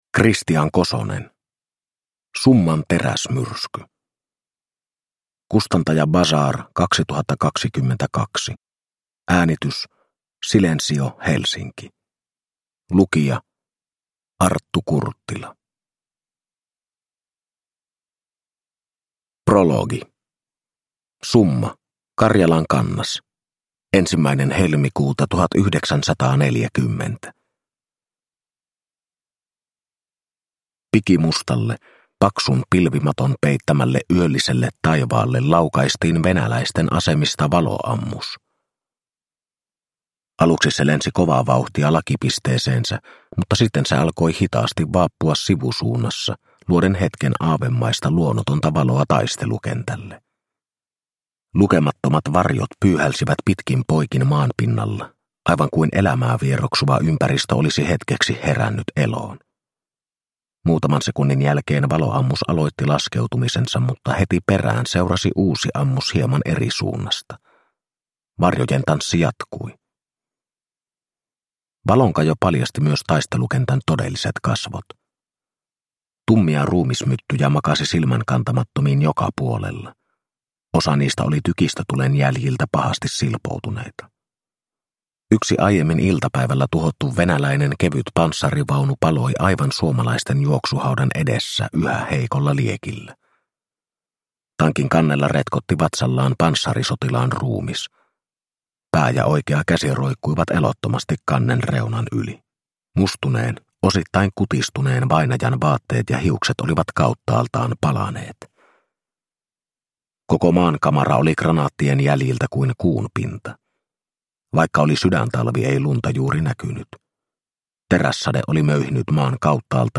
Summan teräsmyrsky – Ljudbok – Laddas ner